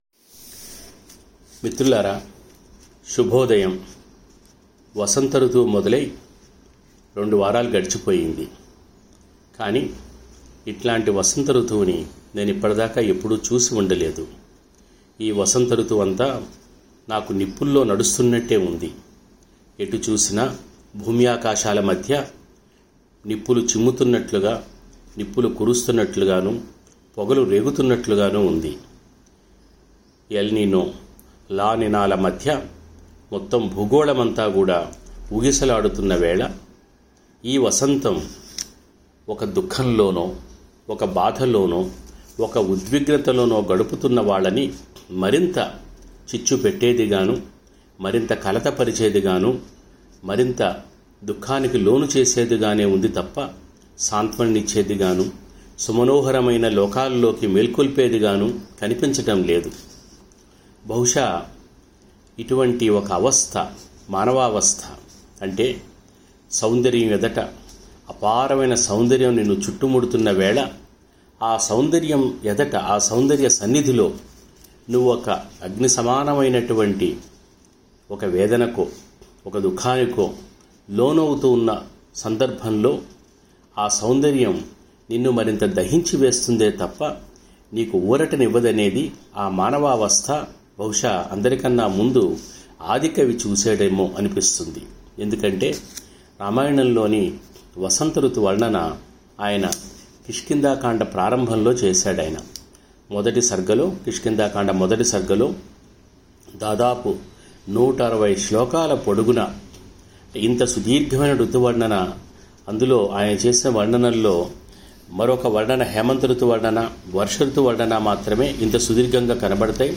రామాయణంలో కిష్కింధాకాండ మొదటిసర్గలో దాదాపు నూట అరవై శ్లోకాల్లో చిత్రించిన వసంతఋతు వర్ణనలోంచి కొన్ని శ్లోకాలూ, కొన్ని దృశ్యాలూ మీతో ఇలా ప్రసంగరూపంలో పంచుకుంటున్నాను.